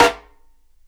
SNARE 2 RIMSHOT.wav